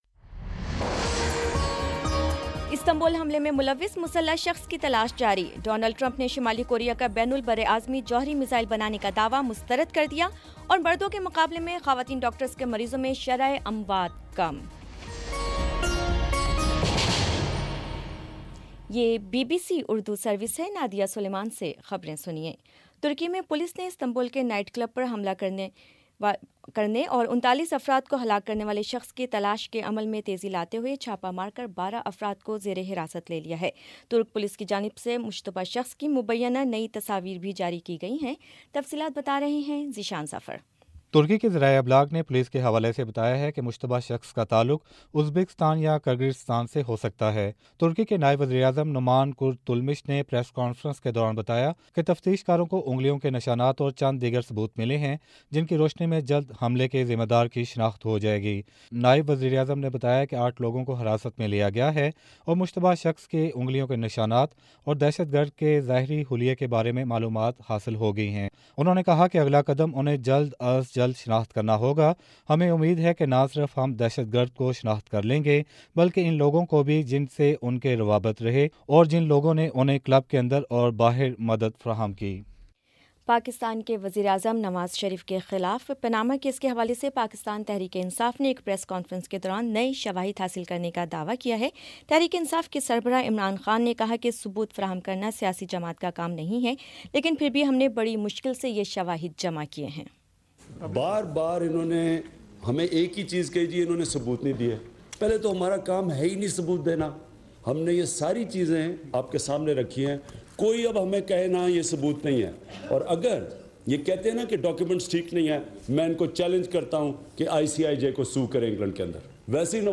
جنوری 03 : شام پانچ بجے کا نیوز بُلیٹن